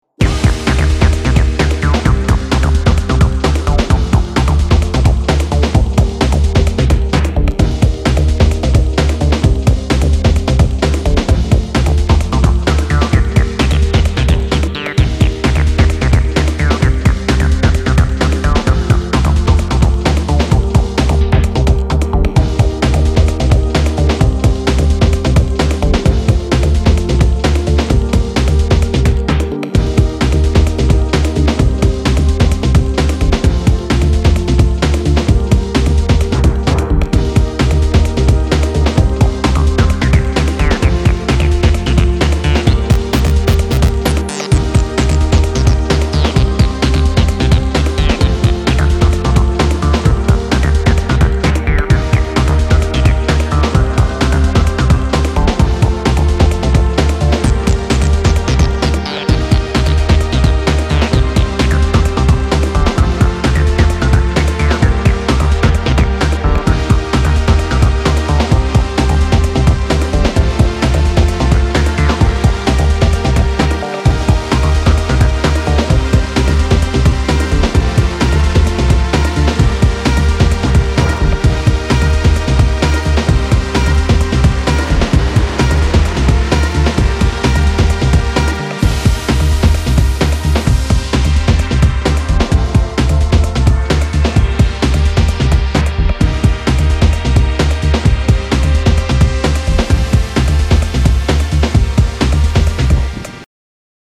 壮大なスケール感のシンセの合間を覚醒的なアシッドが走るブレイクビーツ・ハウス